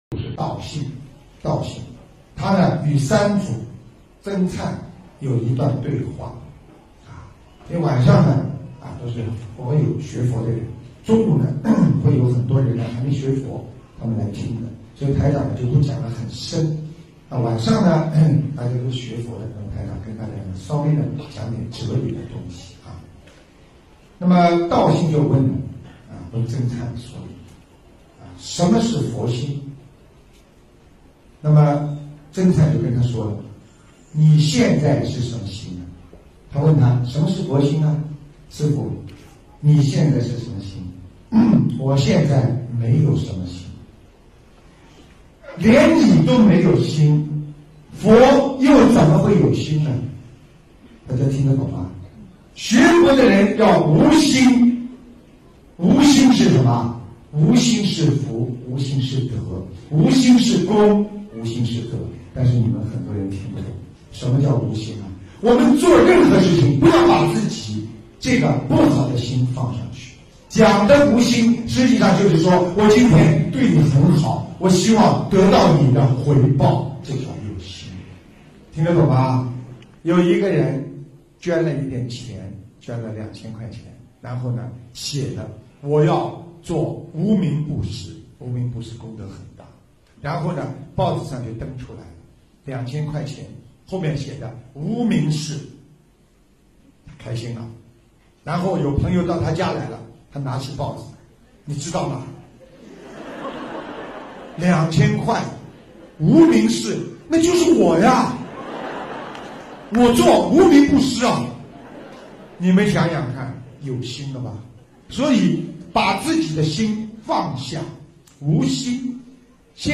音频：四祖道信与三祖僧儏对话·师父讲小故事大道理